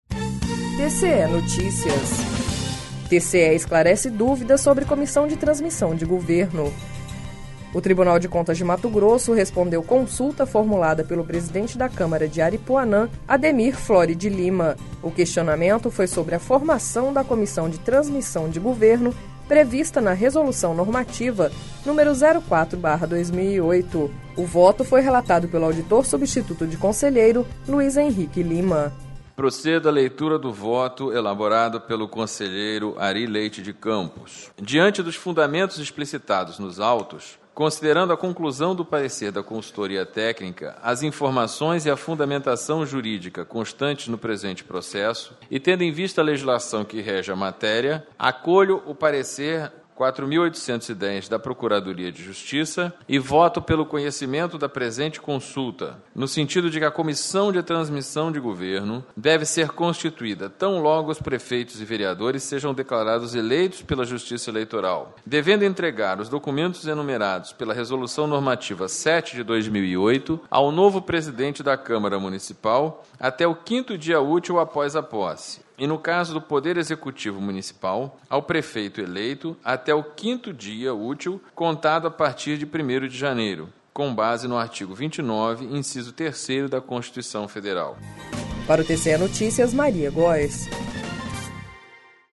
Sonora: Luiz Henrique Lima - auditor substituto de conselheiro do TCE-MT